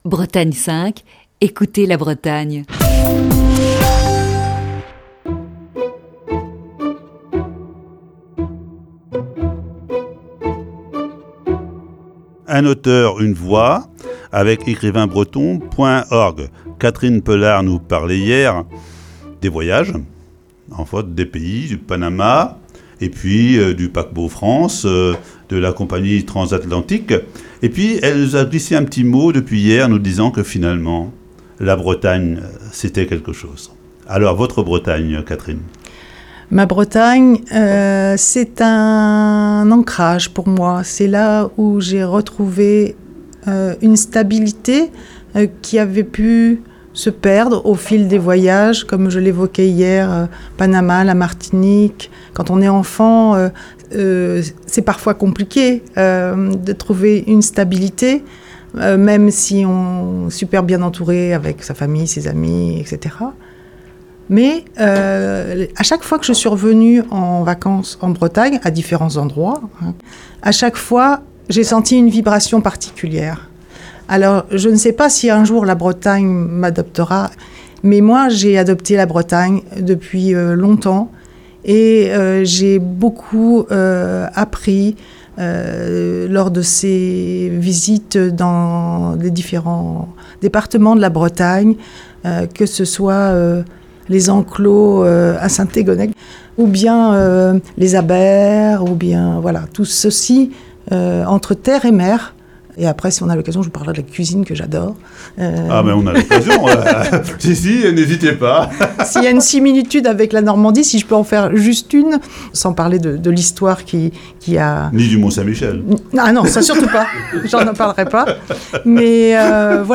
Ce matin, deuxième partie de cet entretien diffusé le 25 février 2020.